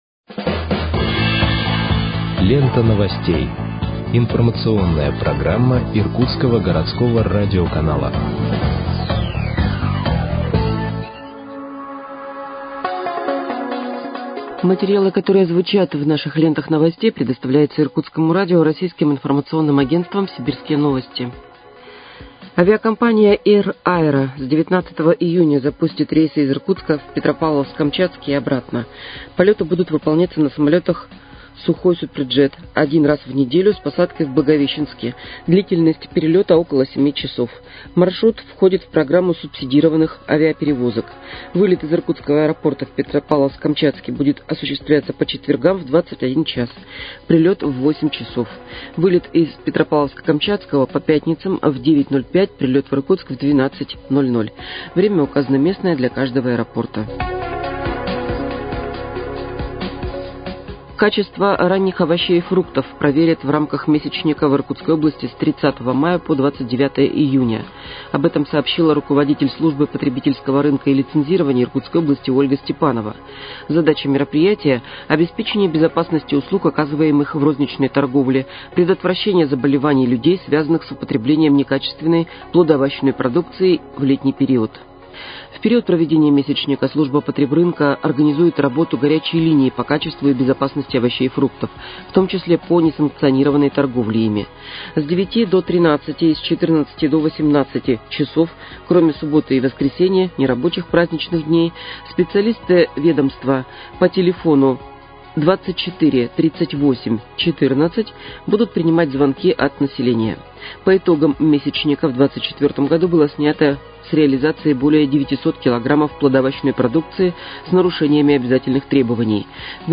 Выпуск новостей в подкастах газеты «Иркутск» от 29.05.2025 № 2